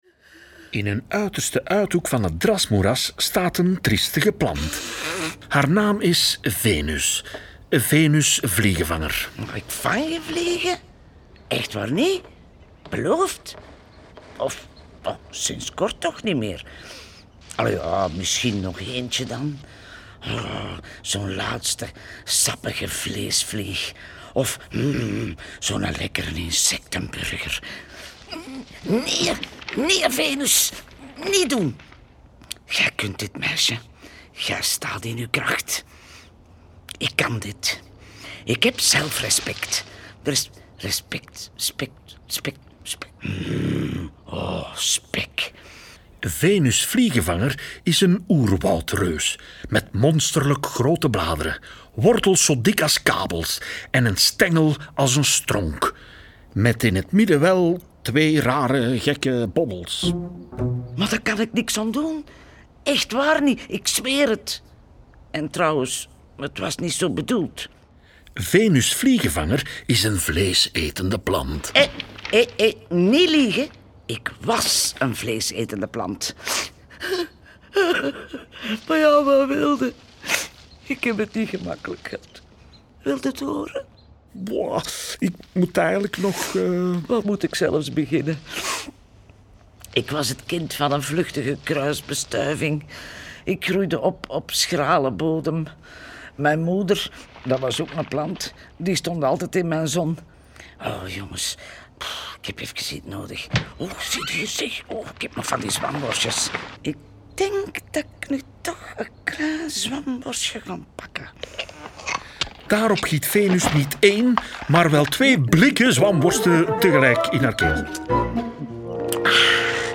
De rollen worden ingesproken door de béste acteurs en in bijhorend prentenboek staat ook een voorleestekst.
Heerlijk hoorspel